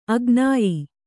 ♪ agnāyi